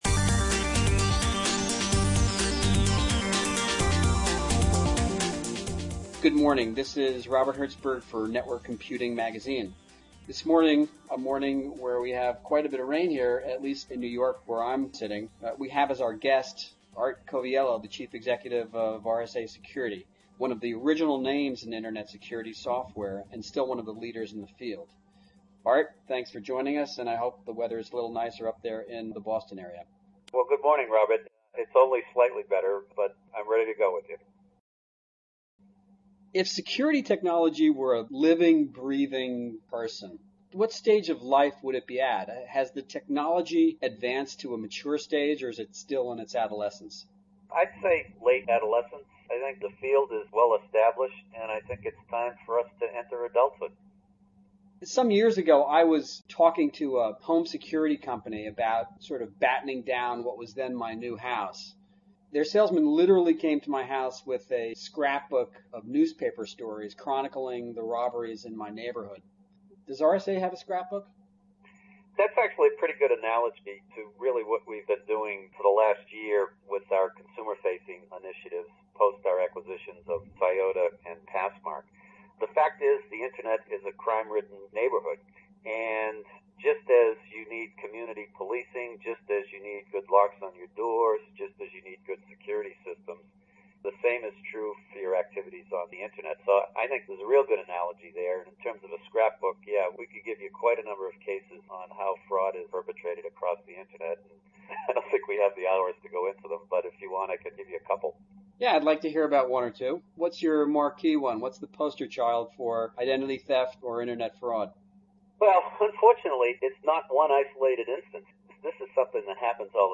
NWC Interview